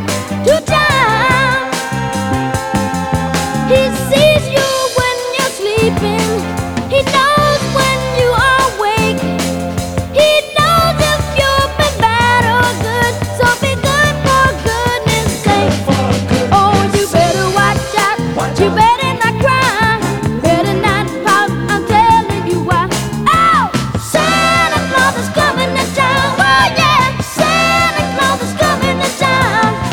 • Oldies